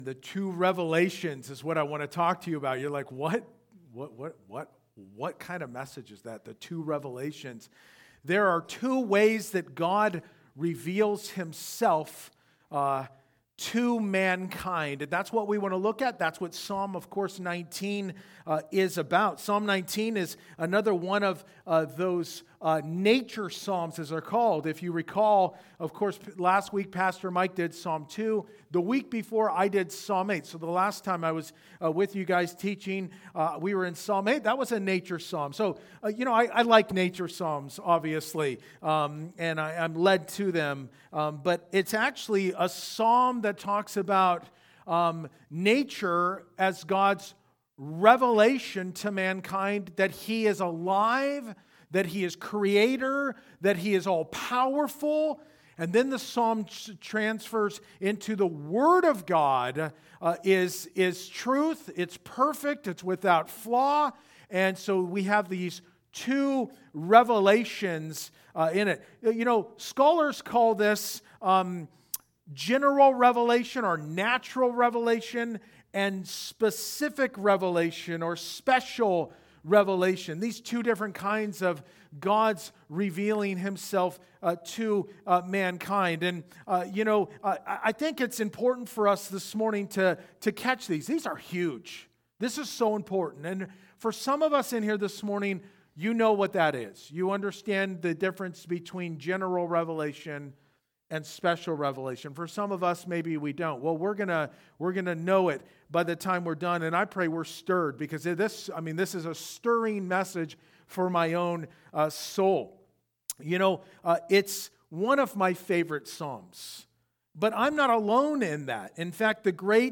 Verse by Verse-In Depth « Psalm 2